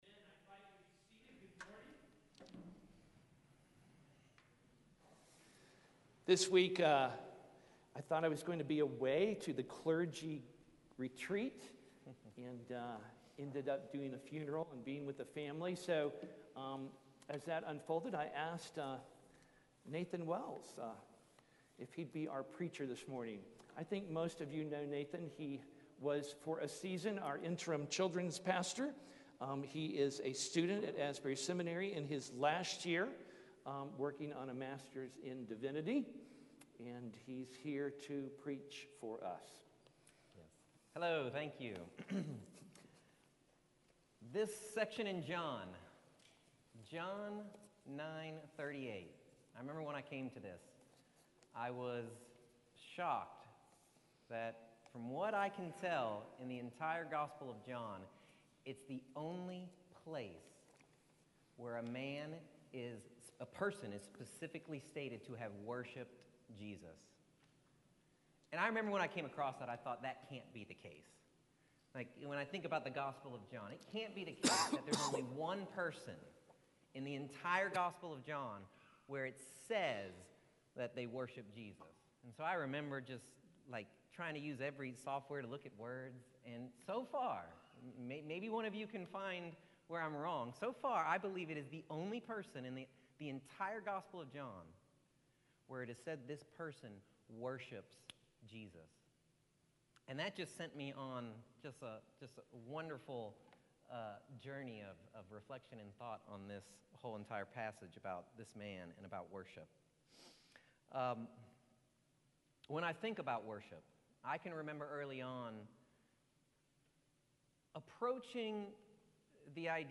Apostles Anglican Church - Lexington, KY